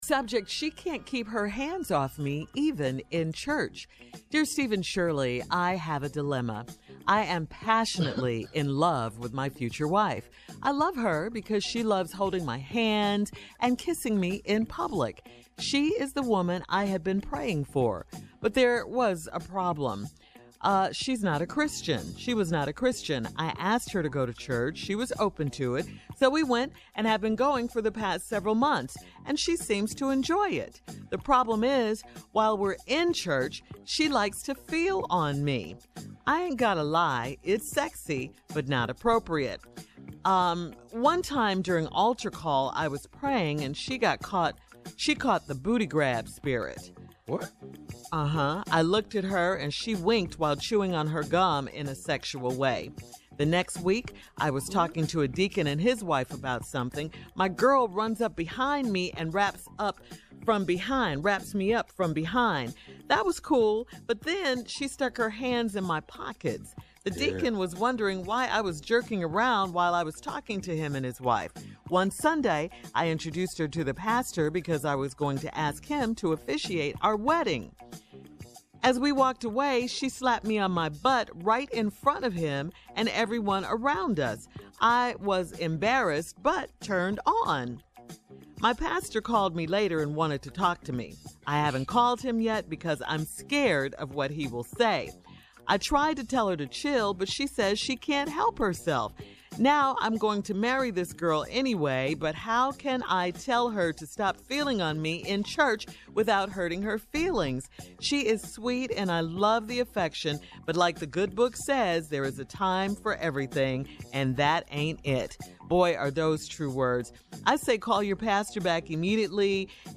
Listen to Shirley and Steve respond to this letter below: